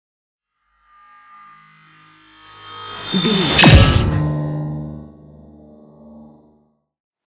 Sci fi whoosh to hit shot
Sound Effects
dark
futuristic
intense
tension
woosh to hit